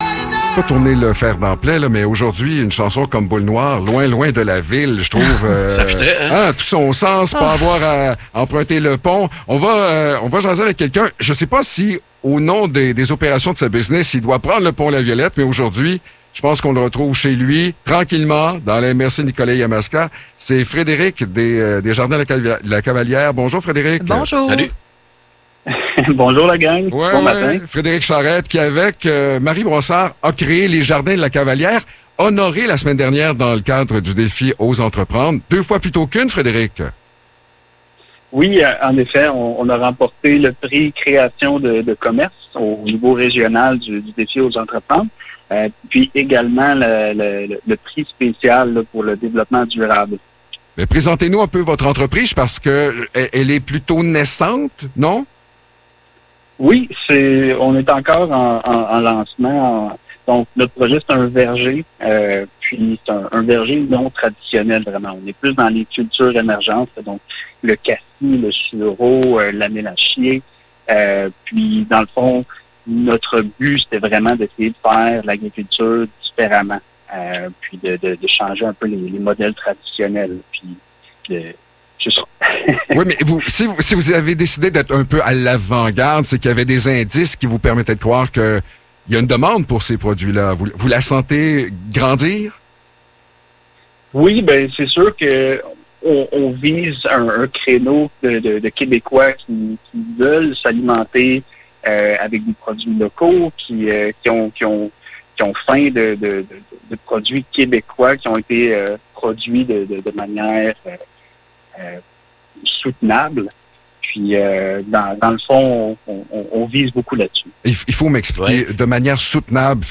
Entrevue : Les jardins de la cavalière